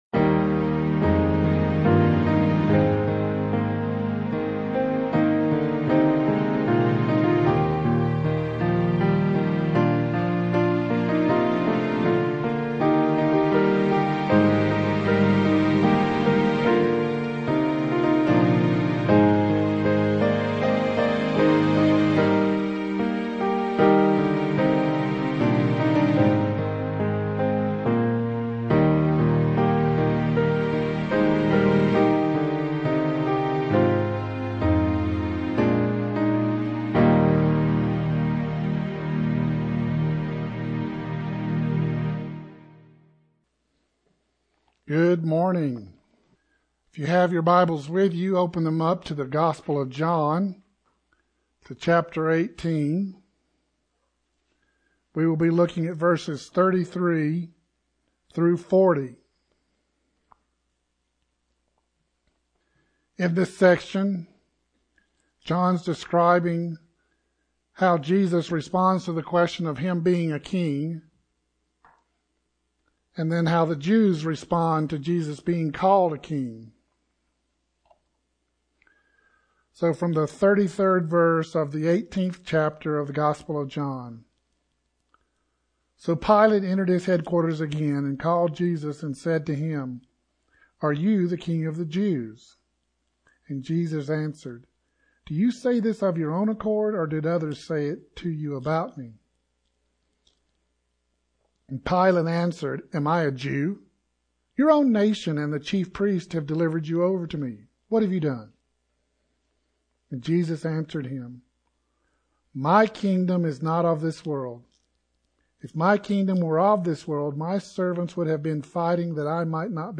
1 Sermon - Christ Kingdom 39:44